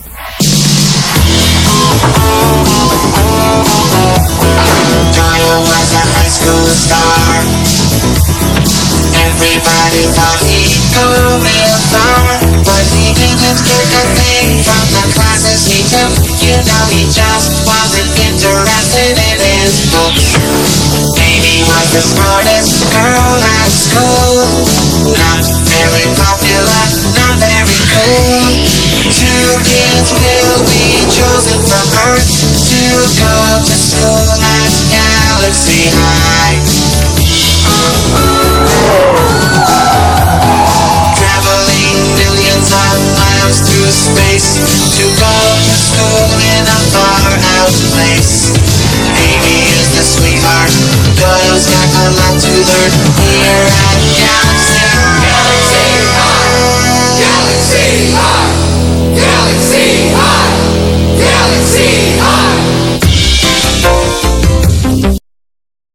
Opening Theme Song!